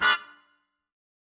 GS_MuteHorn-Ddim.wav